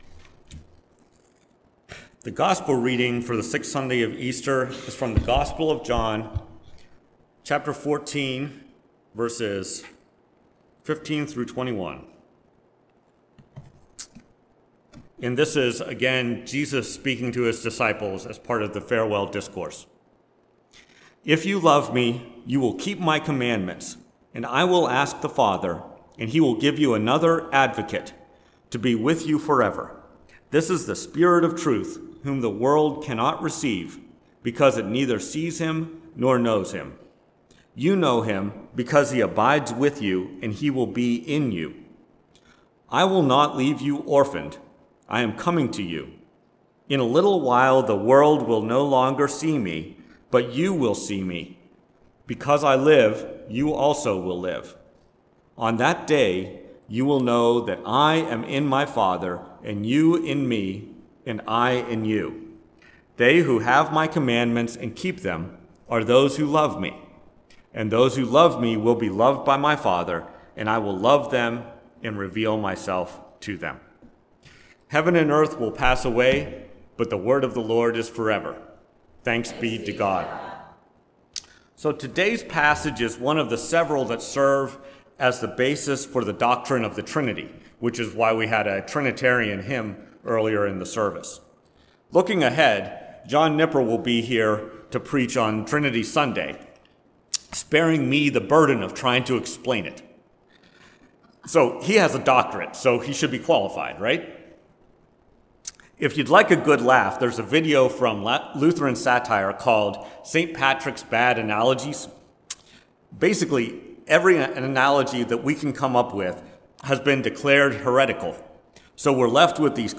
Preached at First Presbyterian Church of Rolla.